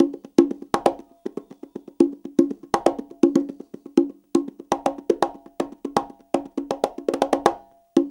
CONGA BEAT36.wav